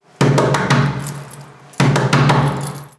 Golpear una puerta de madera con furia
puerta
golpear
madera
Sonidos: Acciones humanas
Sonidos: Hogar